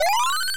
Add a levelup sound
Levelup.mp3